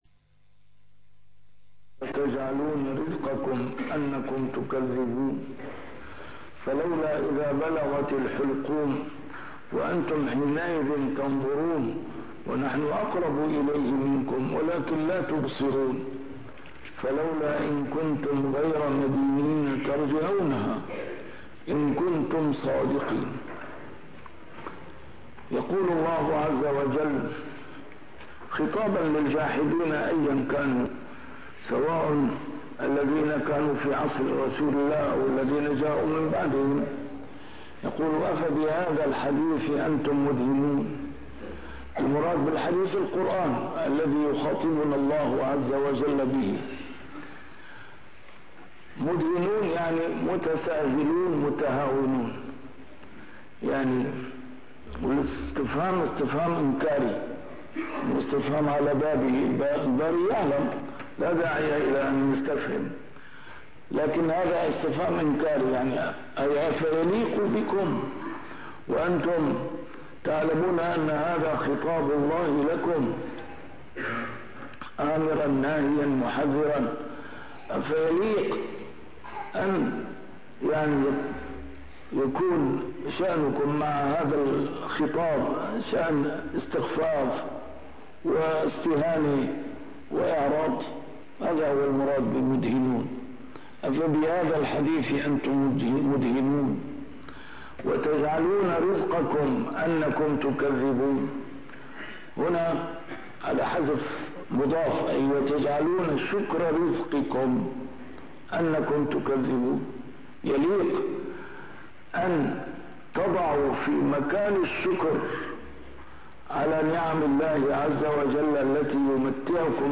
A MARTYR SCHOLAR: IMAM MUHAMMAD SAEED RAMADAN AL-BOUTI - الدروس العلمية - تفسير القرآن الكريم - تسجيل قديم - الدرس 738: الواقعة 81-87